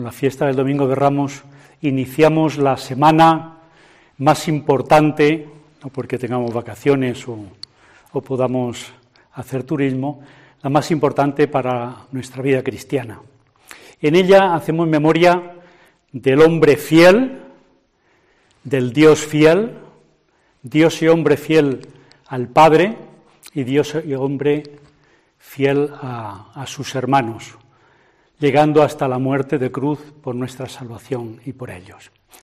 Mensaje del Obispo de Salamanca monseñor José Luis Retana